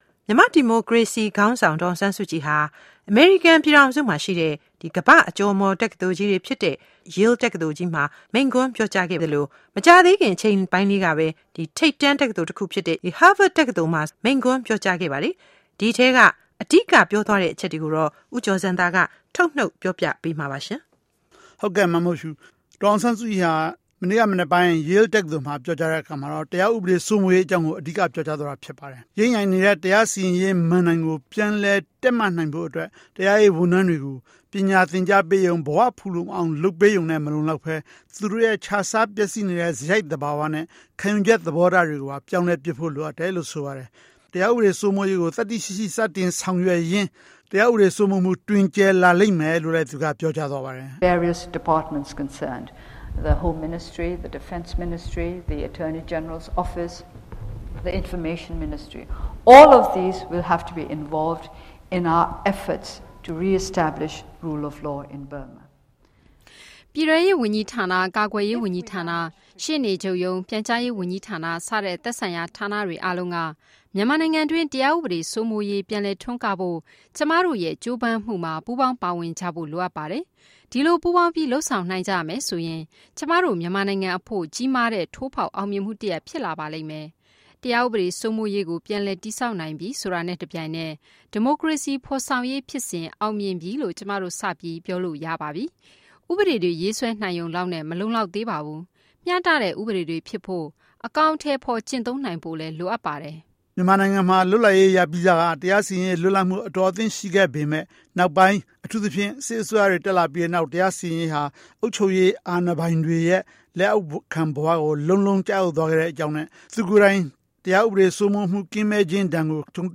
Daw Aung San Suu Kyi's speech at Yale and Harvard University